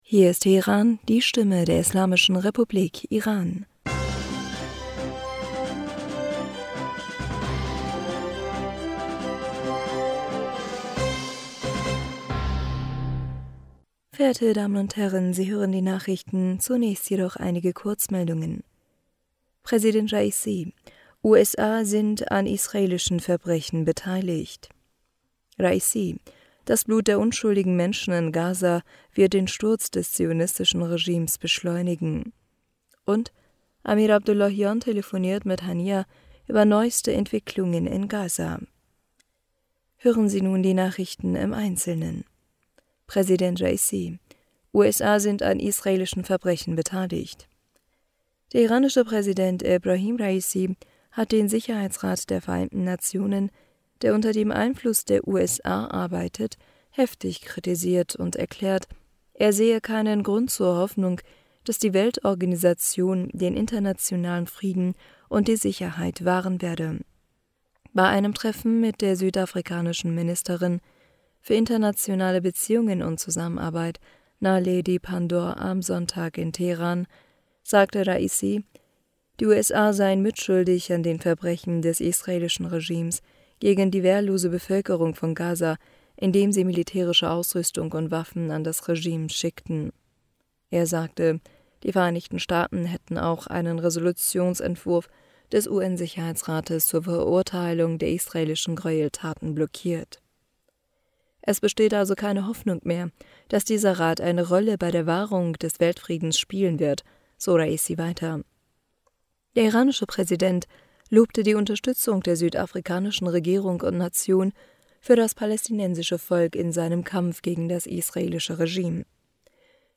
Nachrichten vom 23. Oktober 2023
Die Nachrichten von Montag, dem 23. Oktober 2023